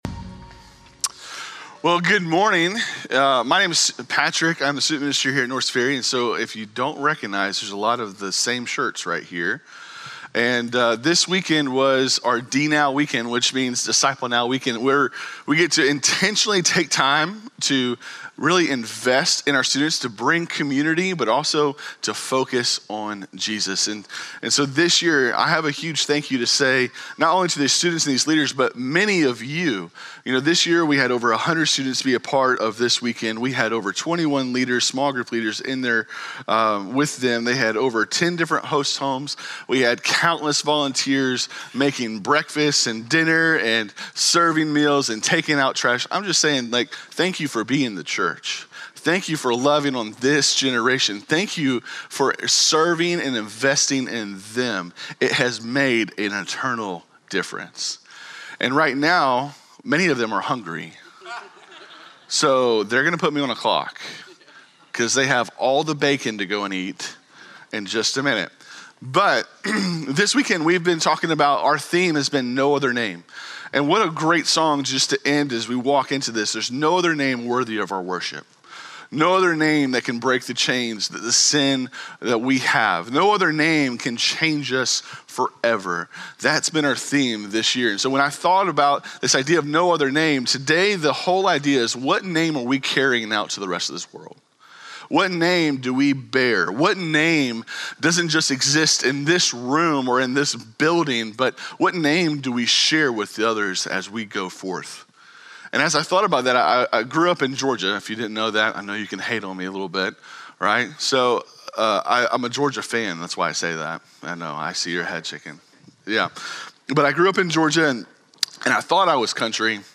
Norris Ferry Sermons Feb. 8, 2026 -- DNOW -- Acts 4:1-21 Feb 08 2026 | 00:36:00 1x 00:00 / 00:36:00 Subscribe Share Spotify RSS Feed Share Link Embed